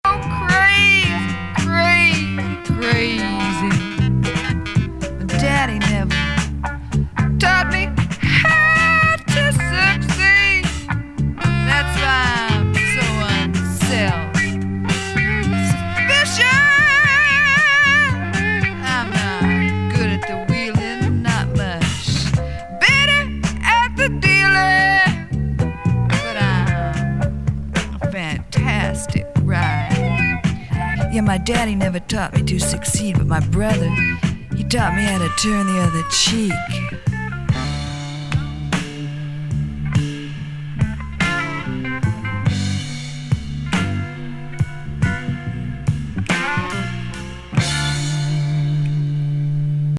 ストレンジ・サイケ・グルーブ